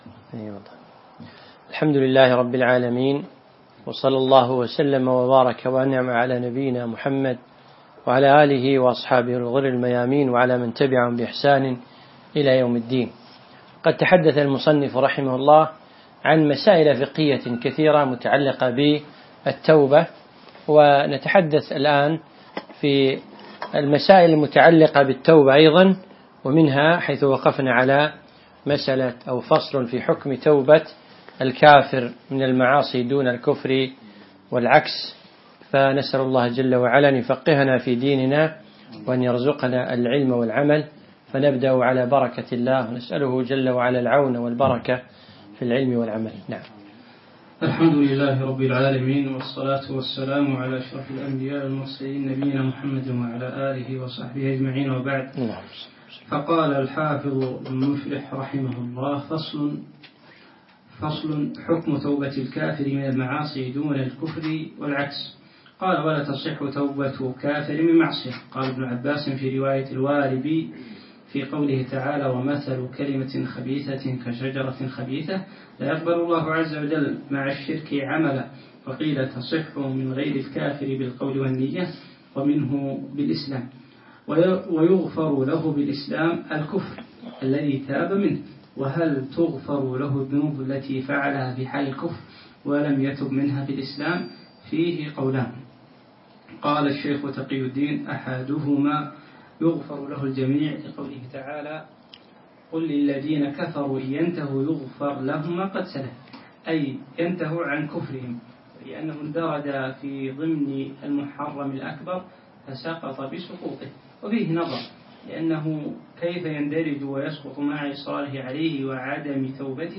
الدرس التاسع